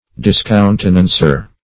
Search Result for " discountenancer" : The Collaborative International Dictionary of English v.0.48: Discountenancer \Dis*coun"te*nan*cer\, n. One who discountenances; one who disfavors.